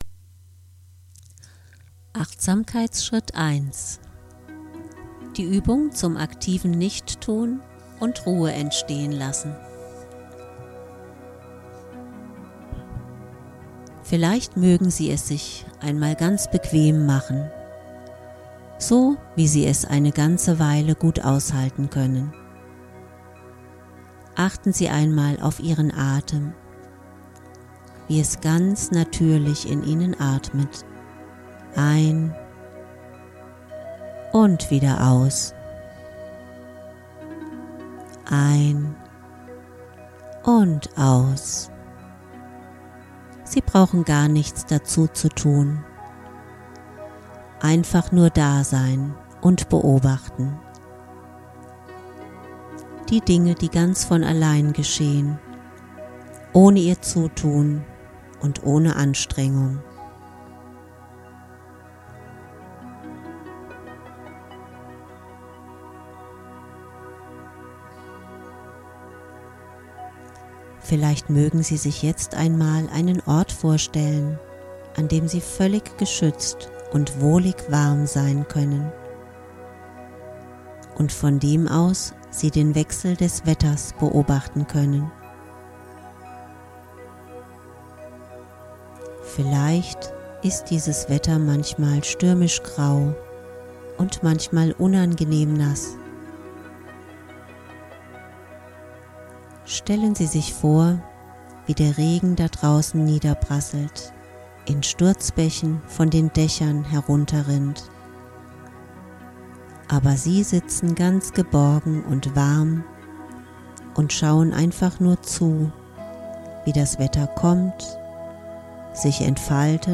Achtsamkeitsübung